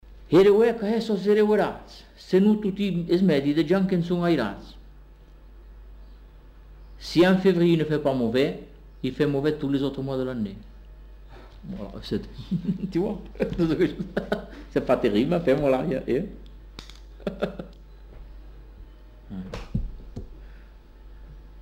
Lieu : Bagnères-de-Luchon
Effectif : 1
Type de voix : voix d'homme
Production du son : récité
Classification : proverbe-dicton